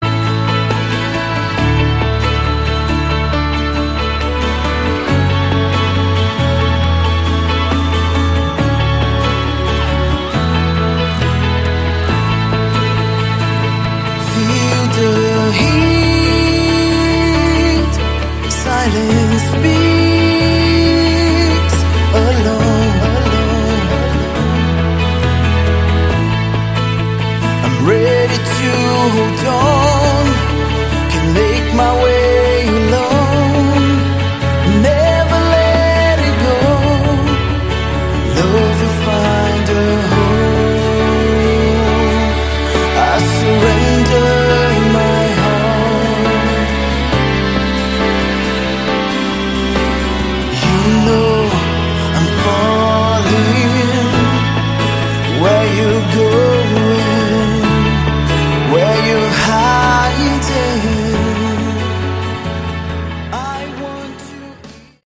Category: AOR
lead vocals
guitars, background vocals
drums, percussion
bass